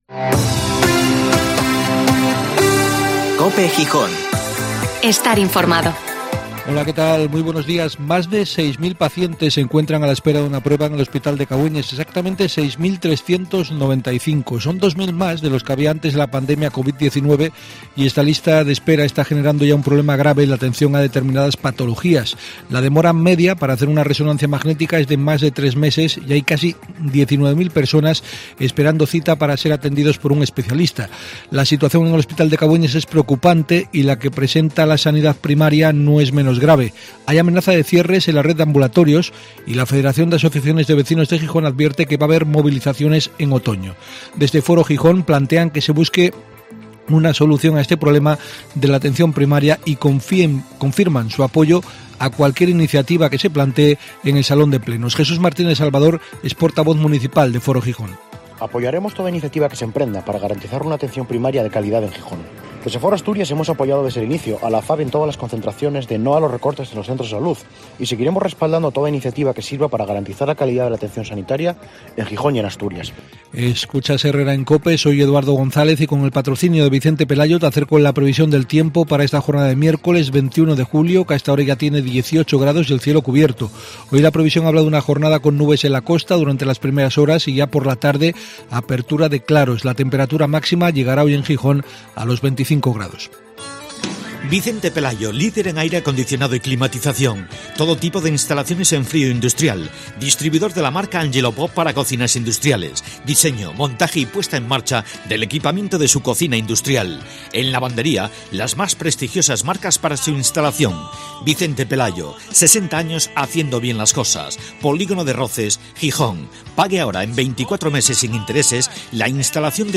Informativo matinal HERRERA en COPE Gijón (Mx 21/Julio/2021)